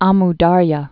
m däryə, ə-m dŭr-yä) Formerly Ox·us (ŏksəs)